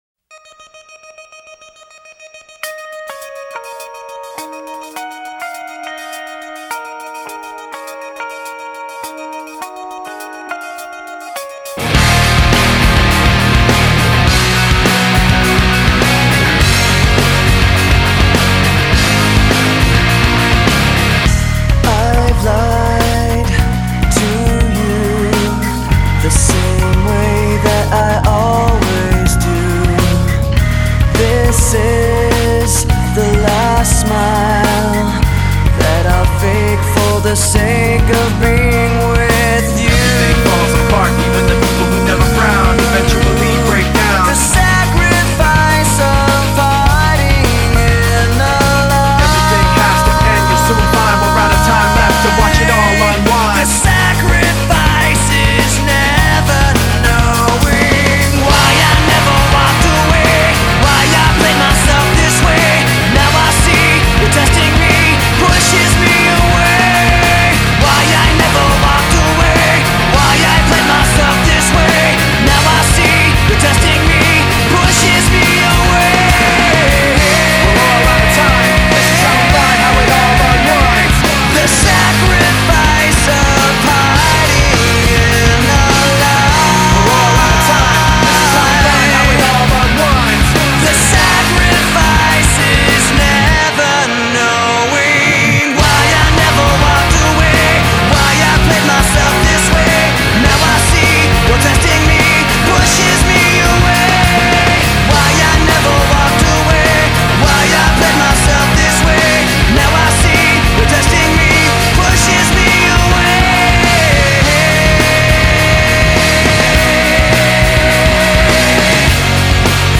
BPM103
Audio QualityMusic Cut